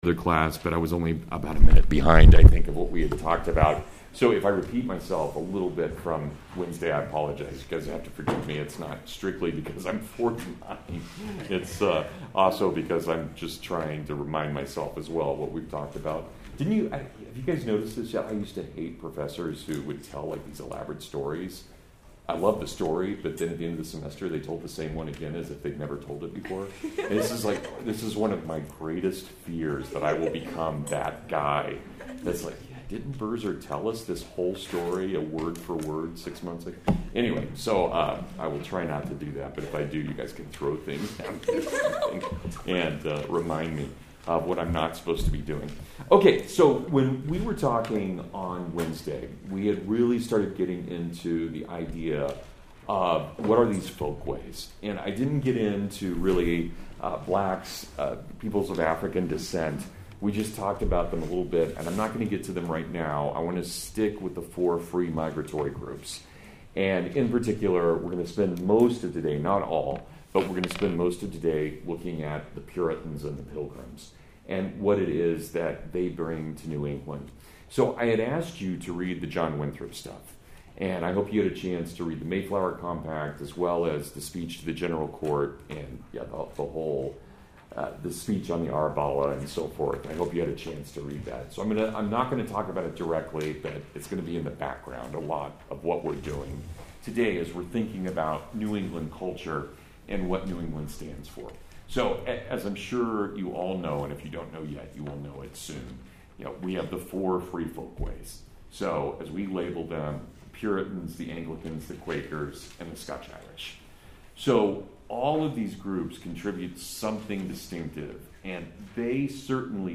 The New England Way (Full Lecture)